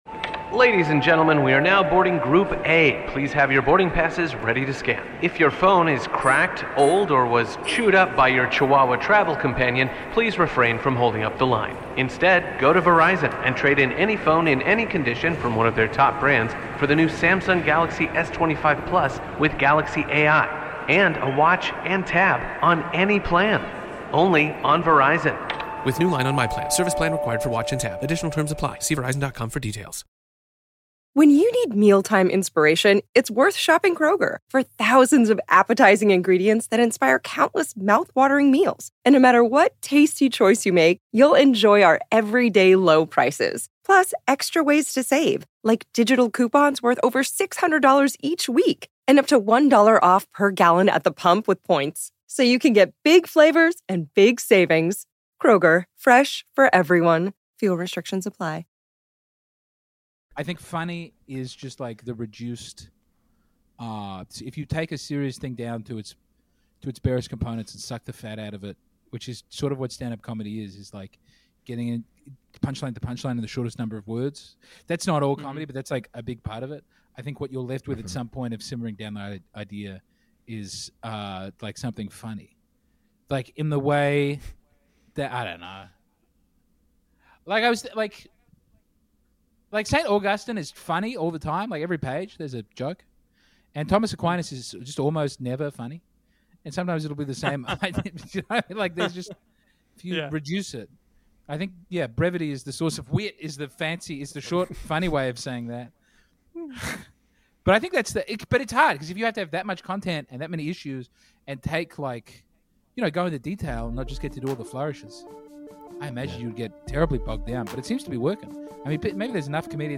A Comedy Podcast for Young Catholics.
Conversations